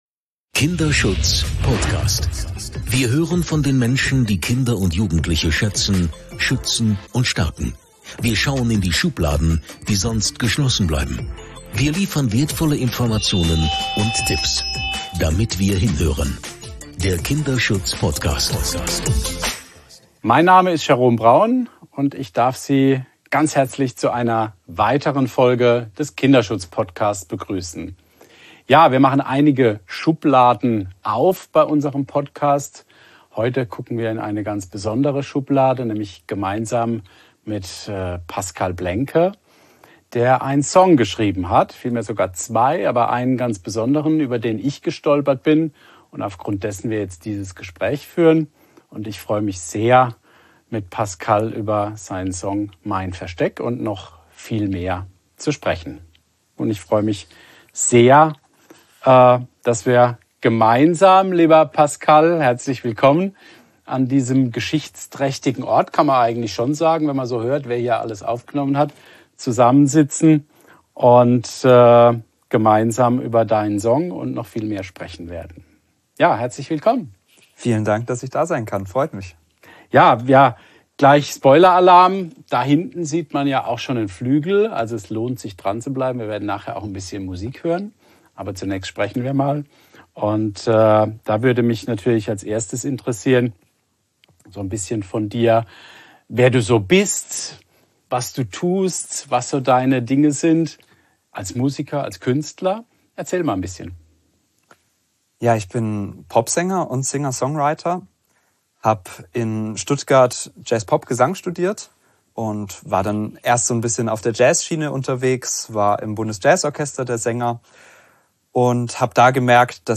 Der letzte Ton verklingt und die erzählten Geschichten hallen nach.